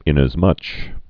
(ĭnəz-mŭch)